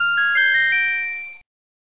1UP Sound Effect — Free Download | Funny Sound Effects
Category 🎮 Gaming
1up levelup sound effect free sound royalty free Gaming